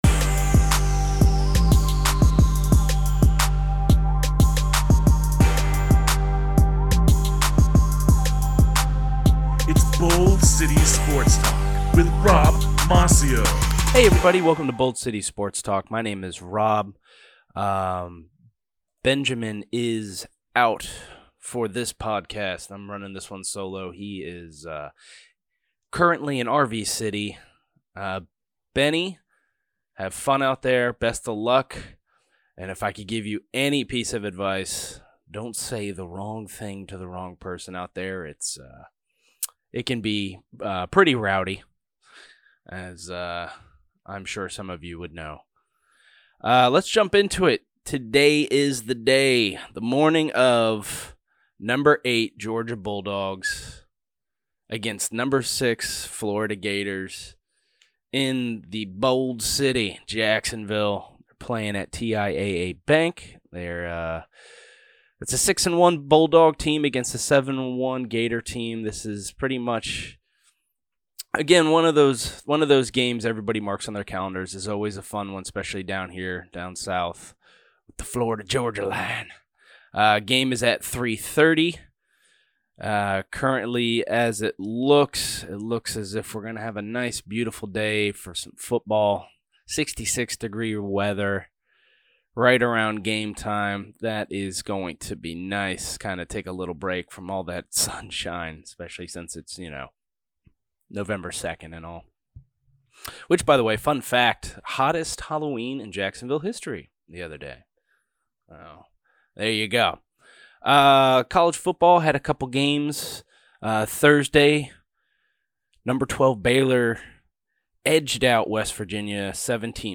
talks solo and gives a FL-GA Final Recap, talks a bit about College Football, the World Series Finale, MLB Free Agents, Los Angeles Lakers, NBA Review, Jimmy Garoppolo, San Francisco 49ers, and a breakdown of the recent NFL News.